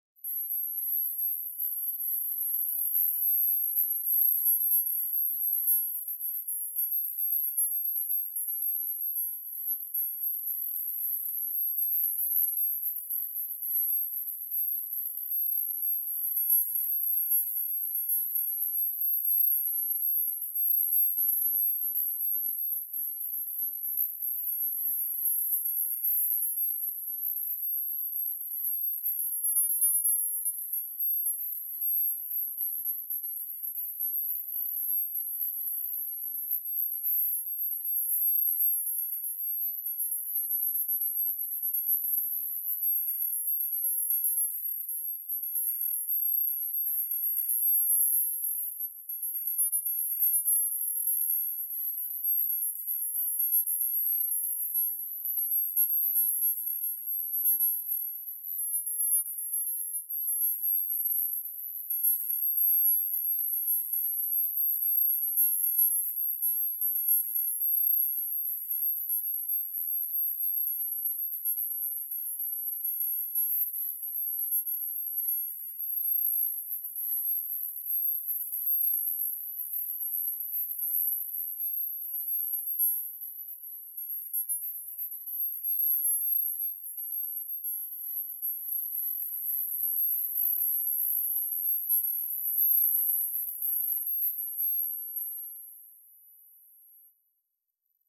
Inversion spectrale
ici un fichier dans lequel le signal audio a subi cette transformation, avec les paramètres suivant: